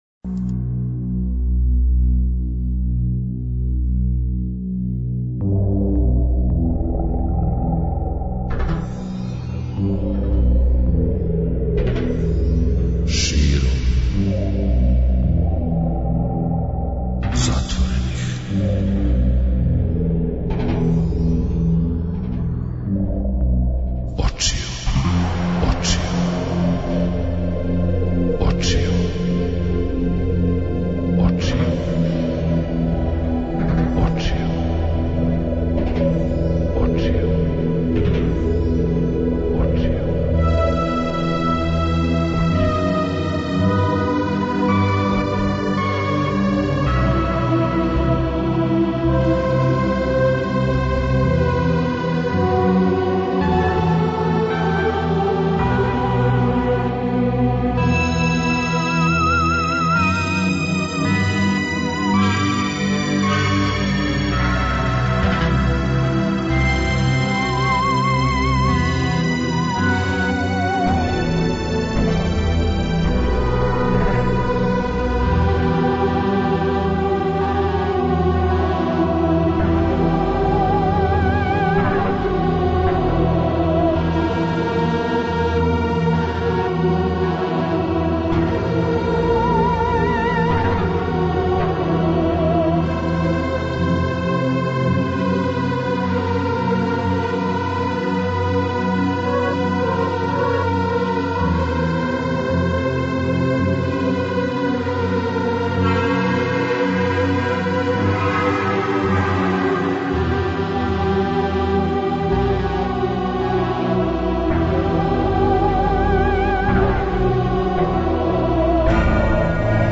преузми : 56.94 MB Широм затворених очију Autor: Београд 202 Ноћни програм Београда 202 [ детаљније ] Све епизоде серијала Београд 202 Устанак Блузологија Свака песма носи своју причу Летње кулирање Осамдесете заувек!